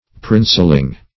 Princeling \Prince"ling\, n. A petty prince; a young prince.
princeling.mp3